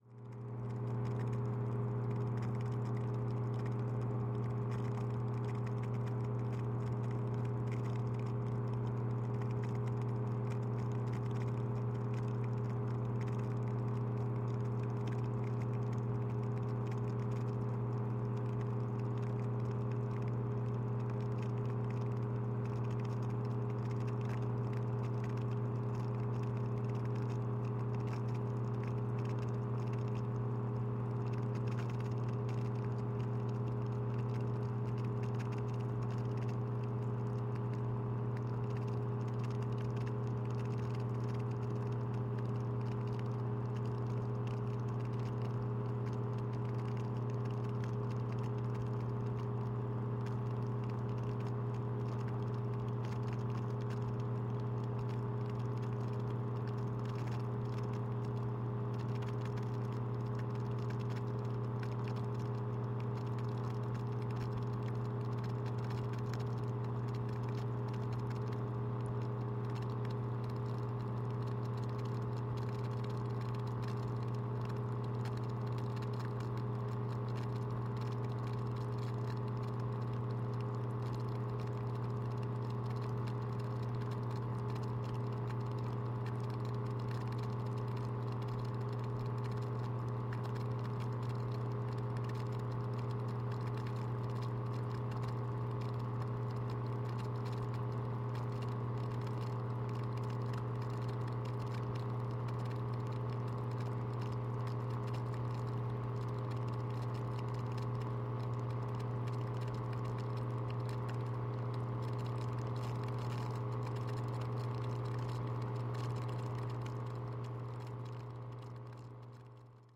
На этой странице собраны звуки работы водяных насосов разных типов.
Откачка воды компактным водяным насосом